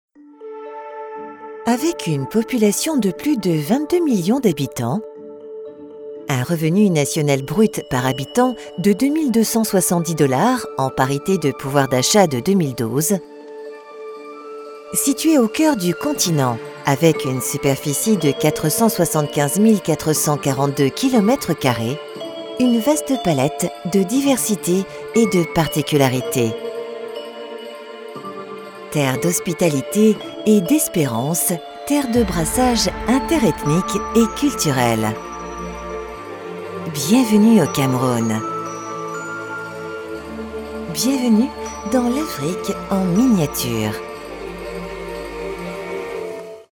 DOCUMENTAIRE